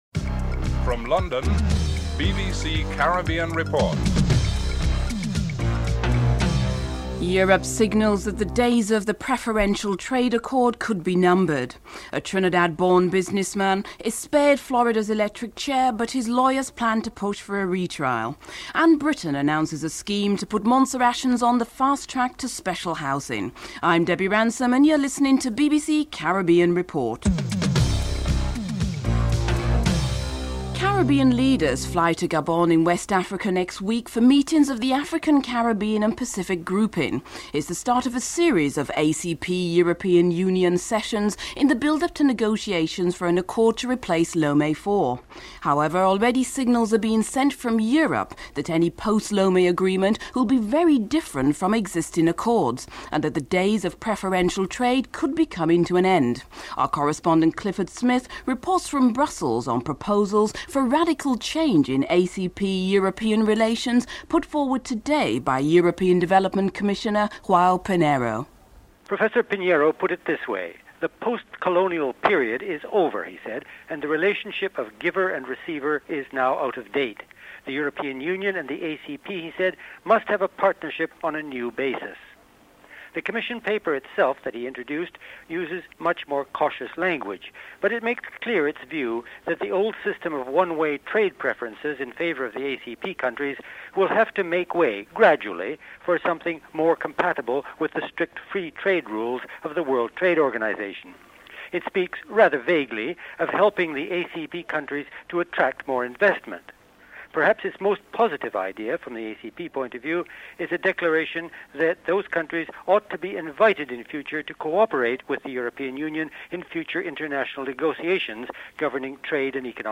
1. Headlines (00:00-00:29)
Stephen Sackur reports (05:17-06:46)